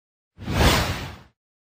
Fast Wind Sound Button - Free Download & Play